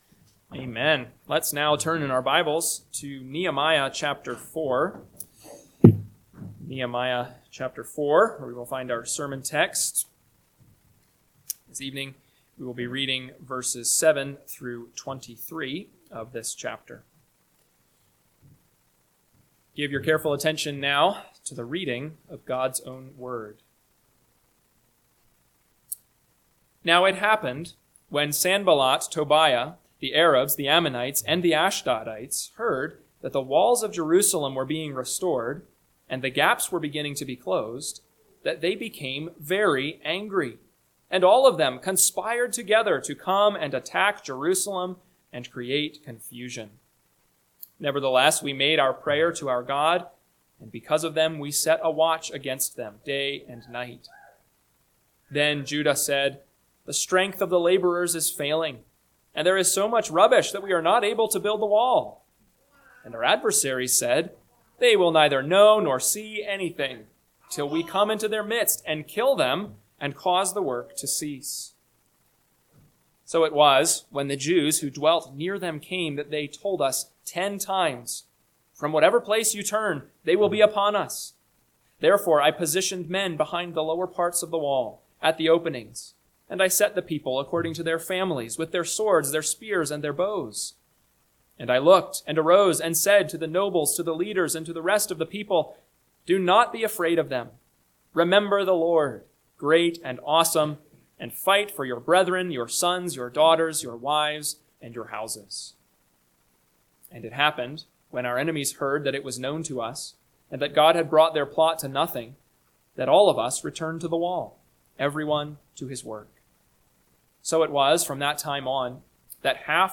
PM Sermon – 7/27/2025 – Nehemiah 4:7-23 – Northwoods Sermons